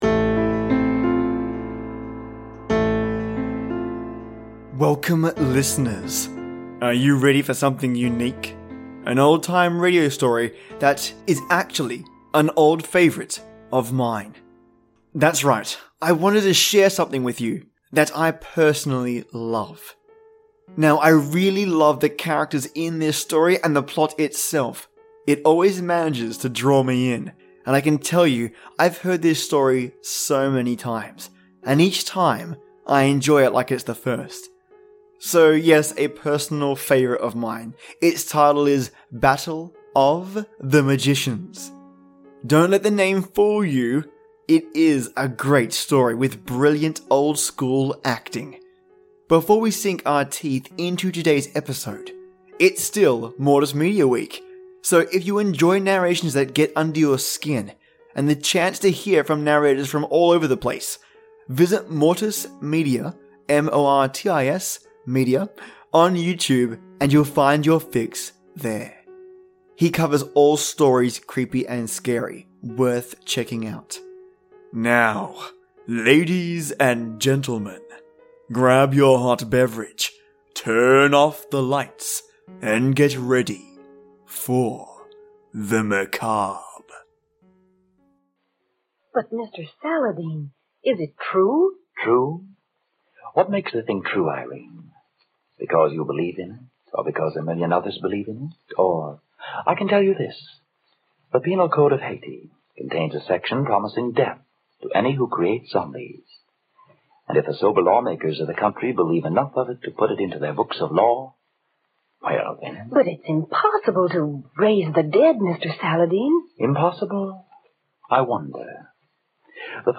Old Time Radio: Battle of the Magicians